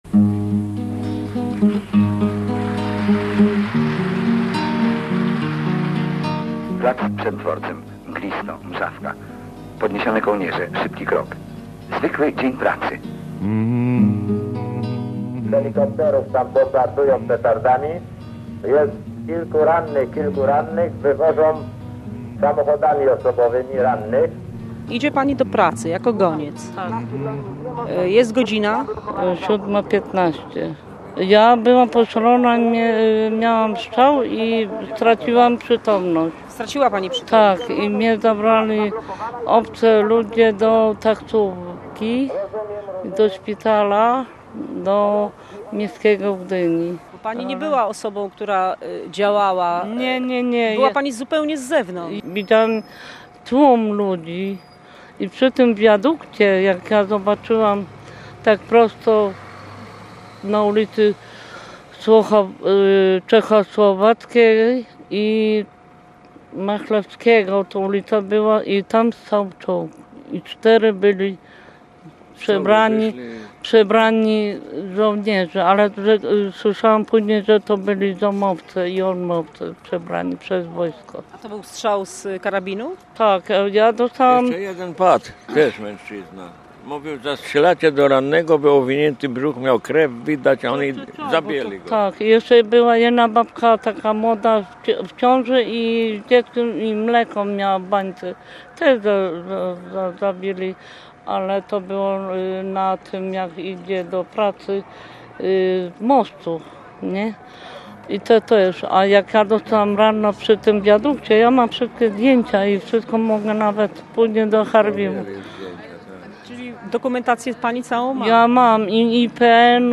Reportaż: Grudzień ’70